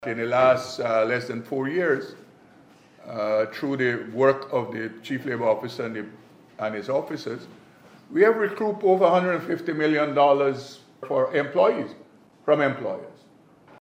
During the official launch ceremony for the Labour Market Information System, Labour Minister Joseph Hamilton described the introduction of the app as a monumental step in their mission to provide relief to workers.